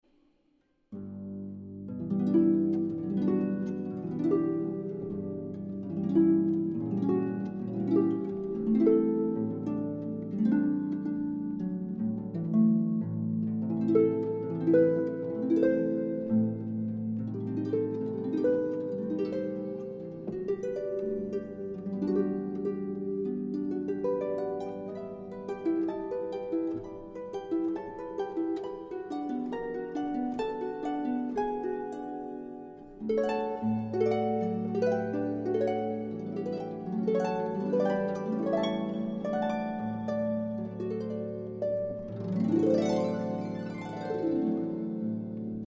Female Solo Harpist